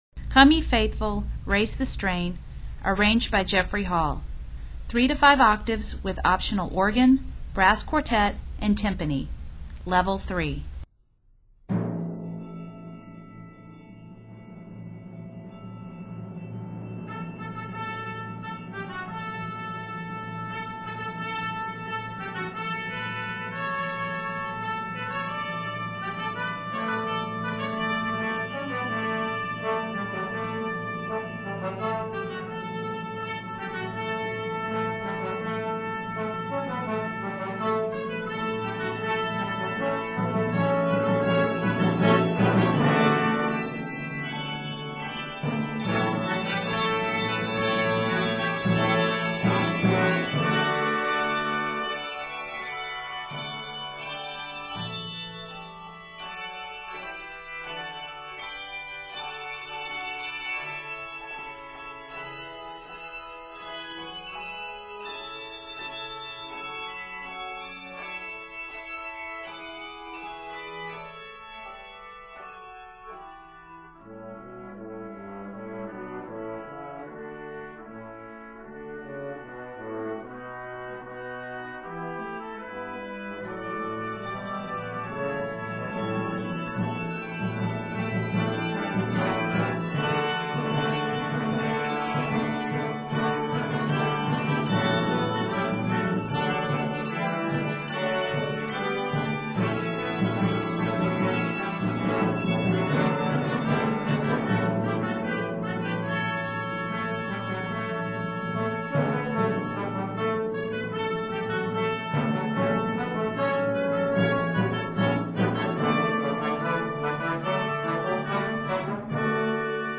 in D Major, E Major, and F Major